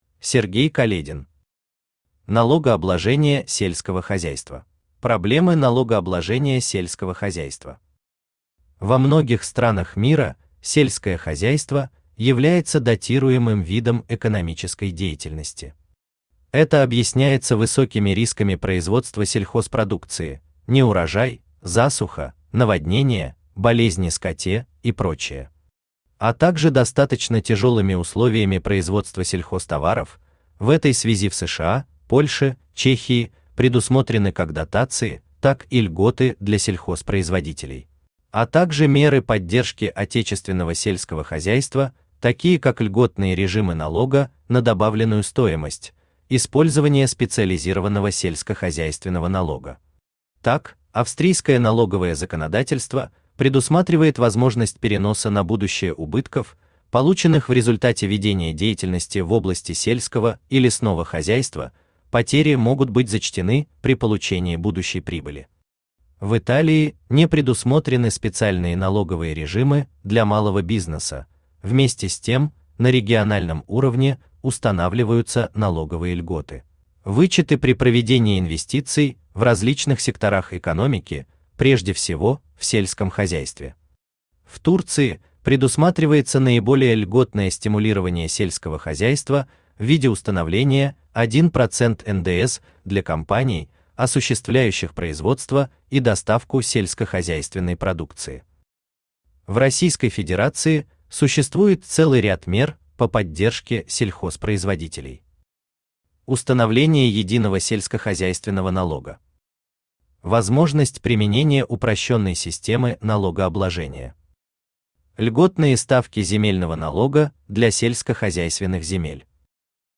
Аудиокнига Налогообложение сельского хозяйства | Библиотека аудиокниг
Aудиокнига Налогообложение сельского хозяйства Автор Сергей Каледин Читает аудиокнигу Авточтец ЛитРес.